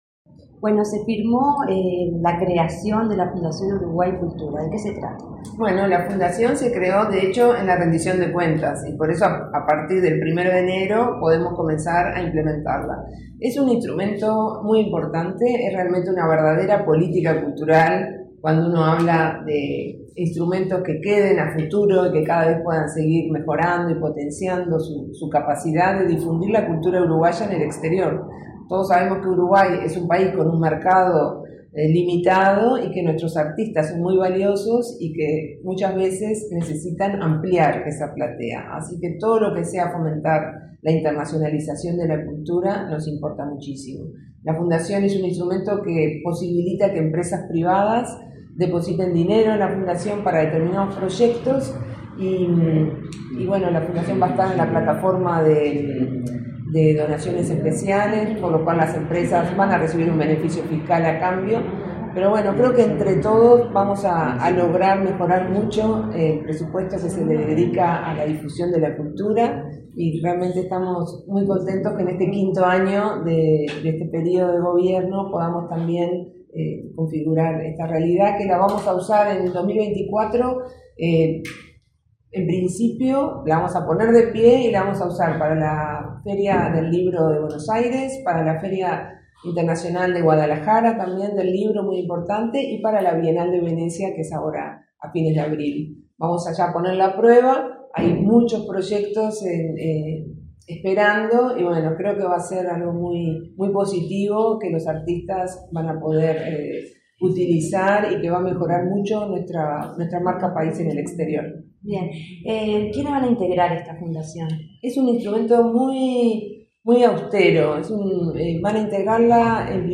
Entrevista a la directora Nacional de Cultura, Mariana Wainstein
La directora nacional de Cultura del Ministerio de Educación y Cultura, Mariana Wainstein, en diálogo con Comunicación Presidencial destacó la
entrevista.mp3